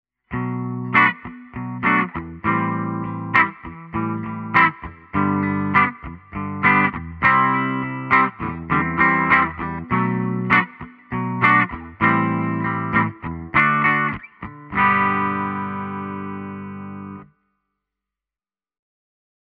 Tokai LC-85 bridge PU clean (tallamikki puhtaana)
tokai-lc-85-bridge-pu-clean.mp3